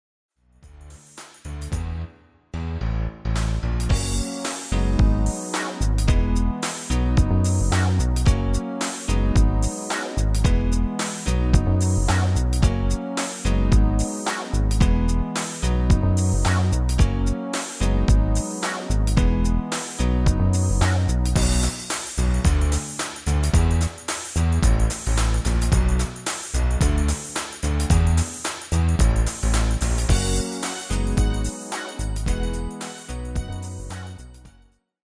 GM-Only MIDI File Euro 8.50
Demo's zijn eigen opnames van onze digitale arrangementen.